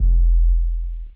808s
longoutboom.wav